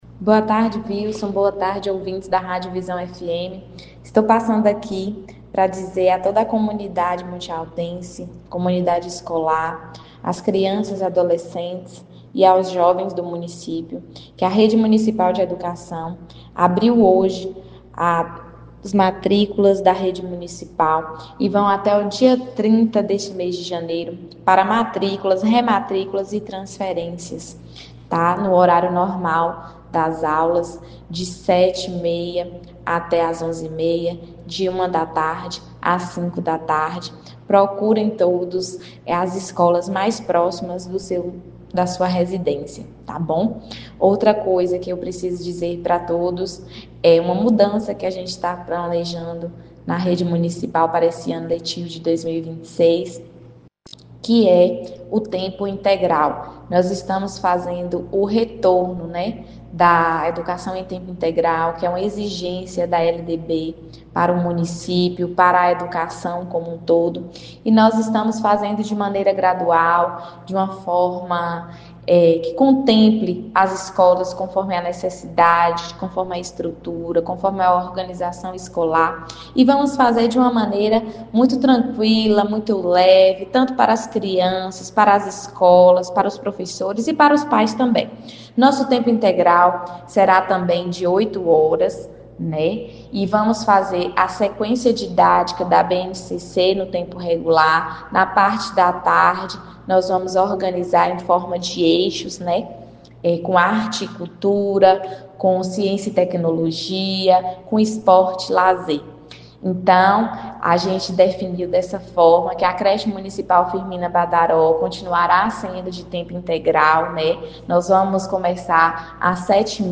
Em áudio enviado ao programa Giro de Notícias, da Rádio Visão FM, a secretária municipal de Educação, Ana Luíza Porto R. Laranjeira Rocha, reforçou o chamado à comunidade escolar e destacou uma das principais novidades para o ano letivo de 2026: a retomada gradual do ensino em tempo integral na rede municipal.
Ao final da entrevista, Ana Luíza reforçou o convite para que famílias que ainda não fazem parte da rede municipal conheçam as escolas e realizem a matrícula dentro do prazo.